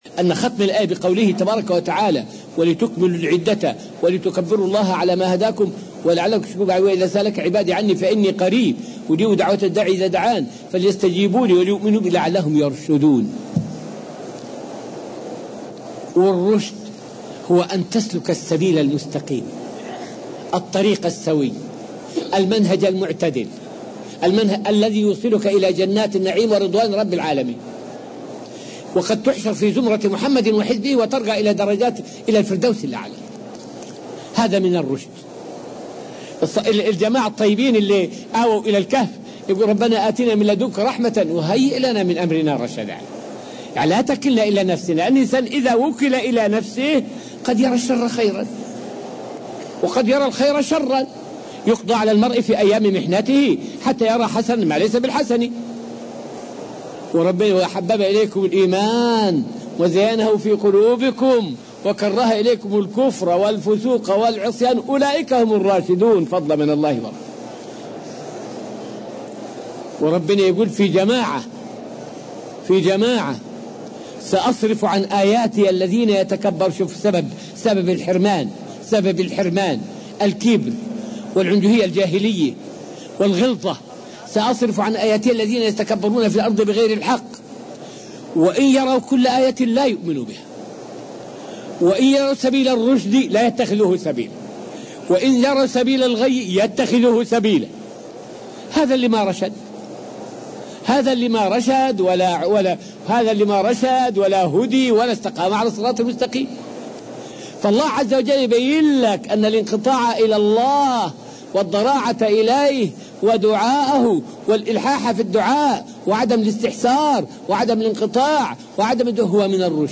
فائدة من الدرس الخامس والعشرون من دروس تفسير سورة البقرة والتي ألقيت في المسجد النبوي الشريف حول معنى قوله تعالى {لعلهم يرشدون}.